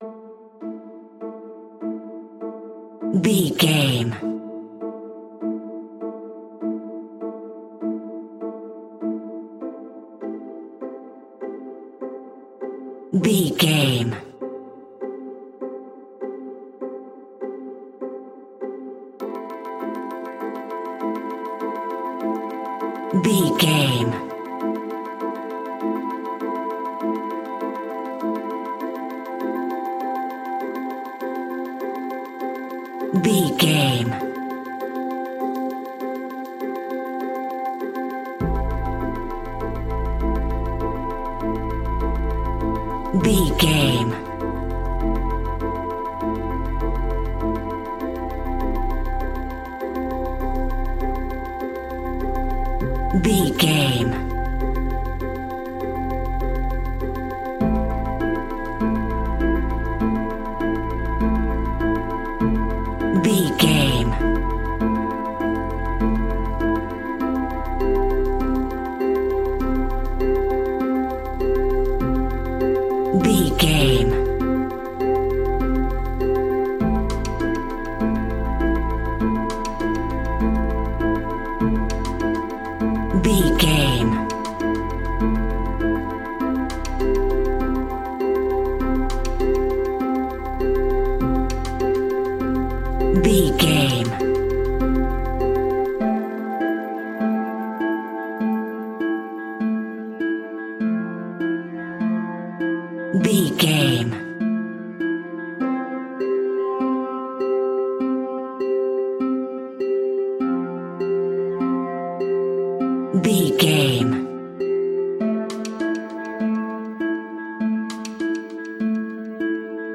Ionian/Major
ominous
dark
haunting
eerie
synthesizer
drum machine
mysterious
horror music